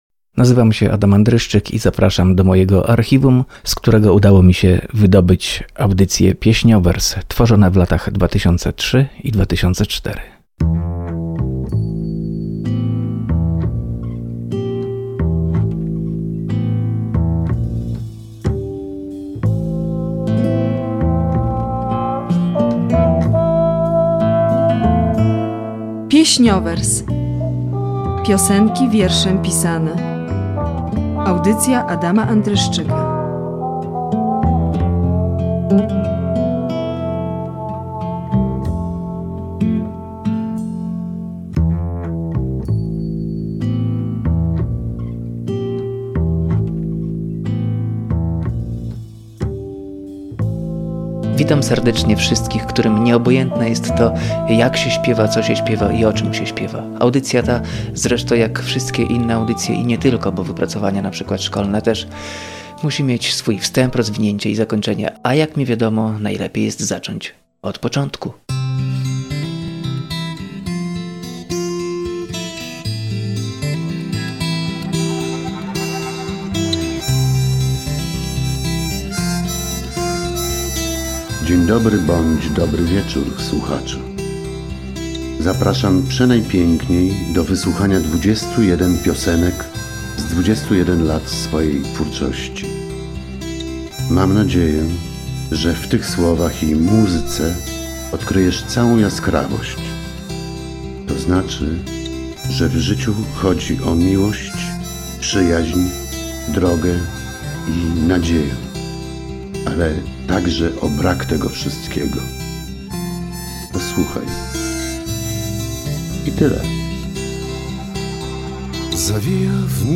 Audycja poświęcona piosence literackiej, tworzona w latach 2003-2004 dla Radia Olsztyn.